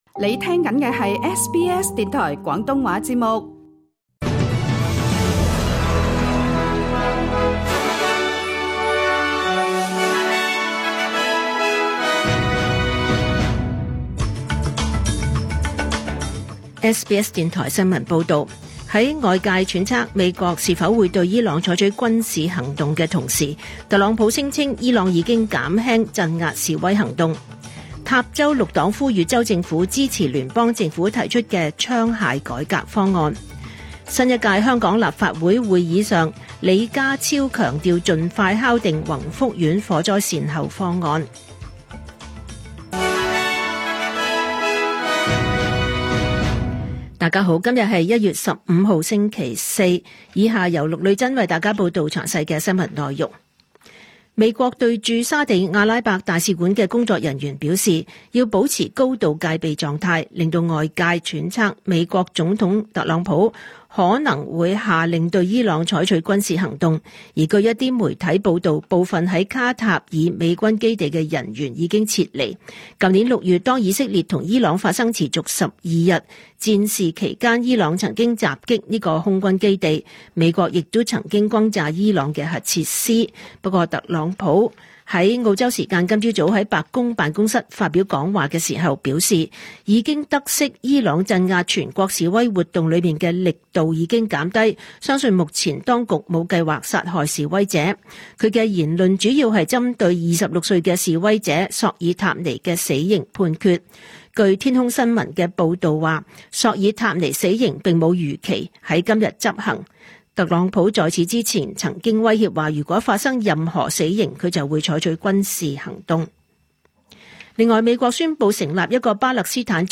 2026 年 1 月 15 日 SBS 廣東話節目詳盡早晨新聞報道。